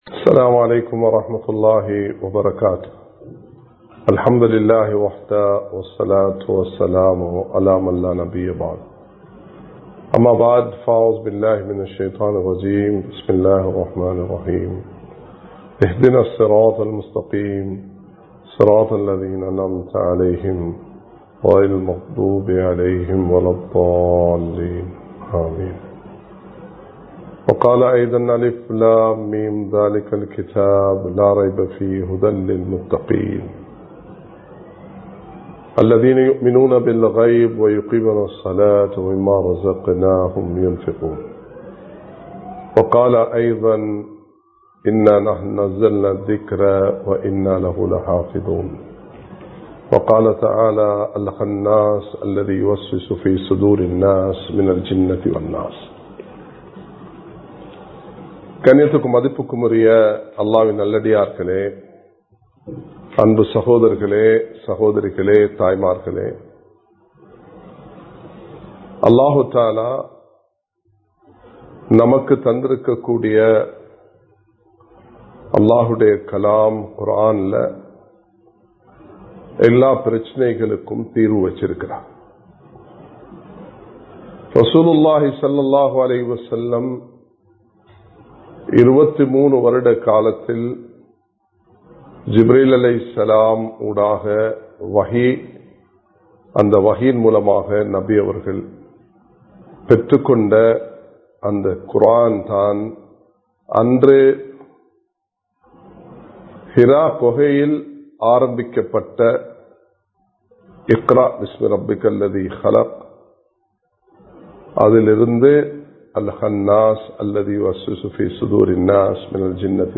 மார்க்கம் சொல்லும் வழிகாட்டல்கள் | Audio Bayans | All Ceylon Muslim Youth Community | Addalaichenai
Live Stream